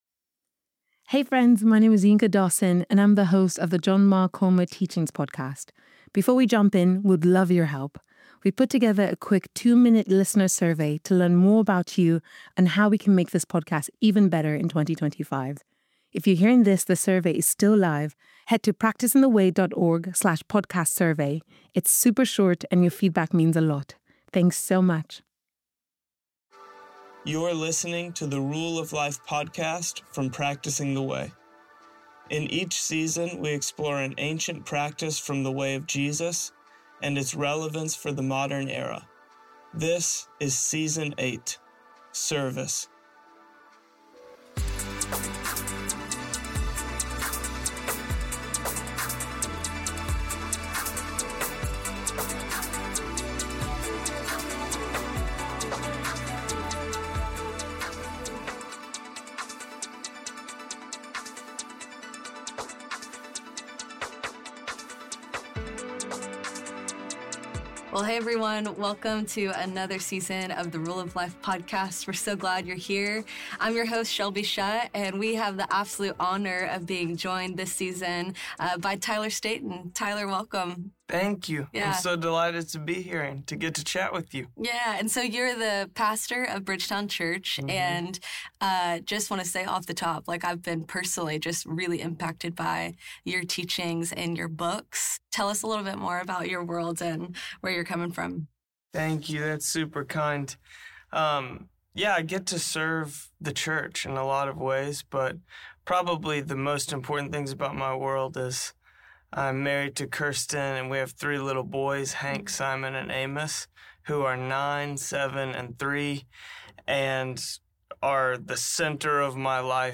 featuring conversations with everyday people in the West, testimonials from real-life practitioners, and an interview with luminary Gary Haugen.